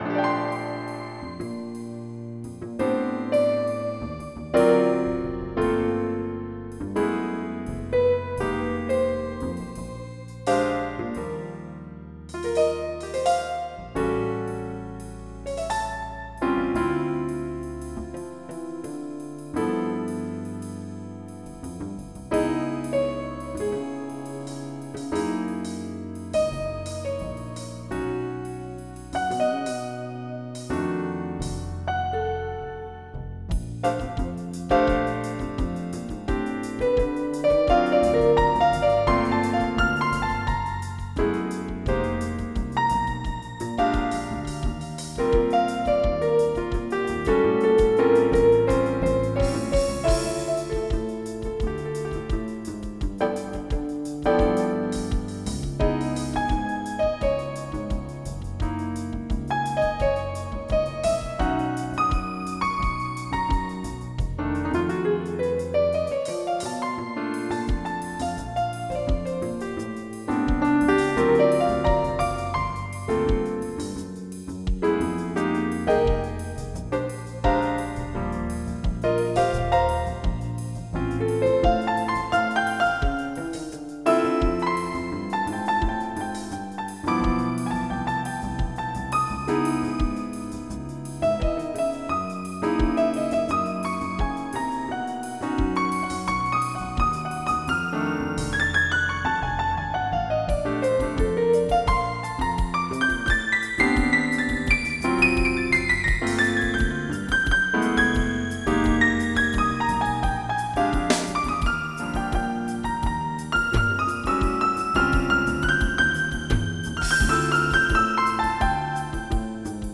Jazz
General MIDI